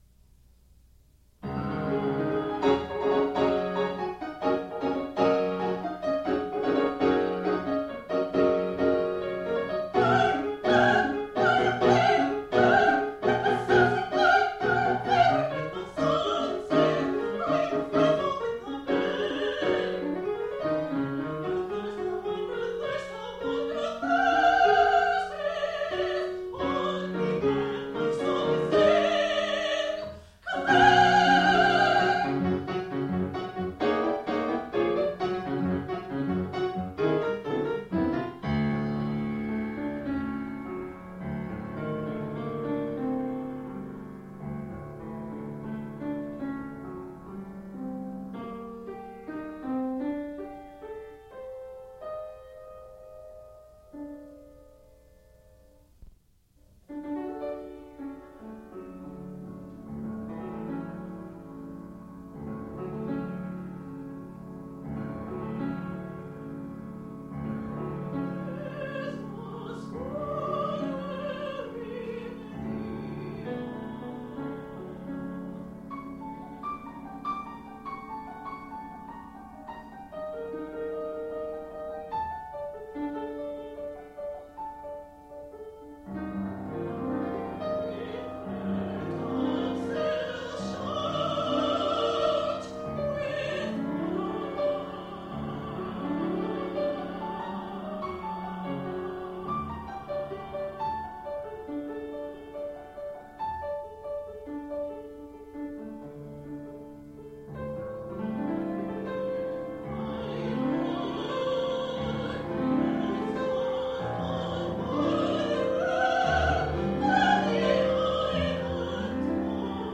SONG CYCLES